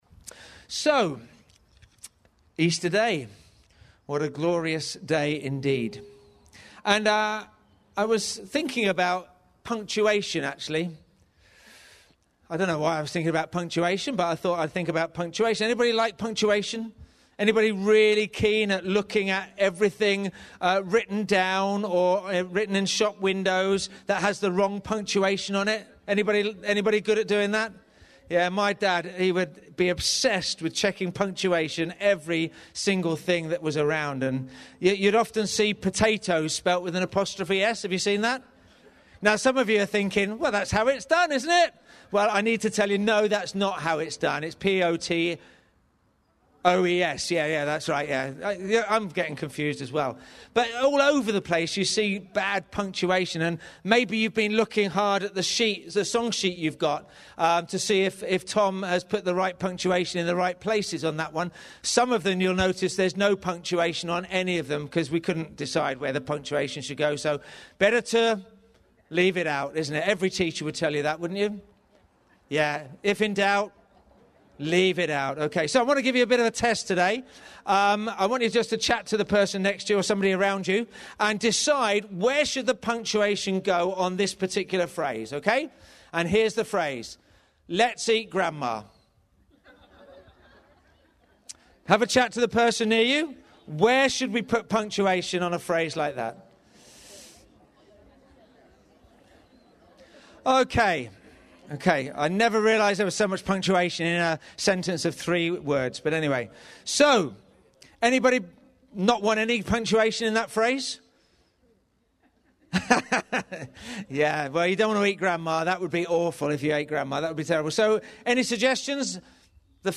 Today we're celebrating the moment death was defeated with us live from our car park!
NBC-Easter-Sunday-2022.mp3